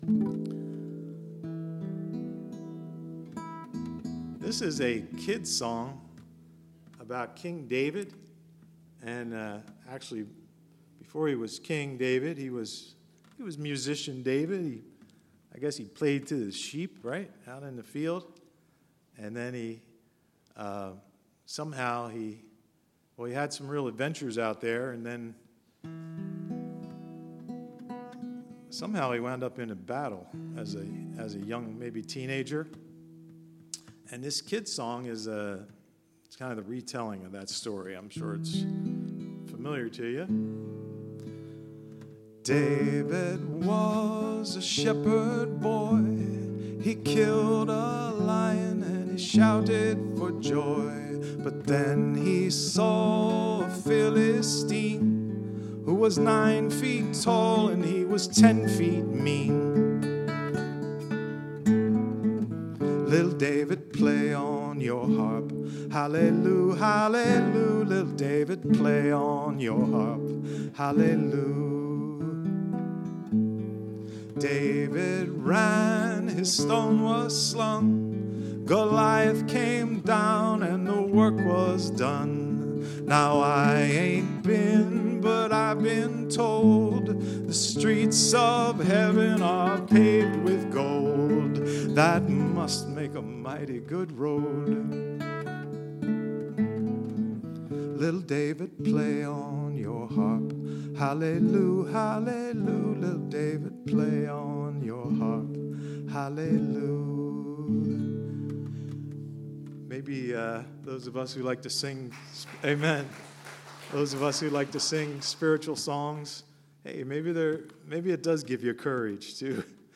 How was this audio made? Voice, Guitar and Recorder- Laurel Hill Bible Church Seniors Luncheon in Clementon,NJ October 10, 2017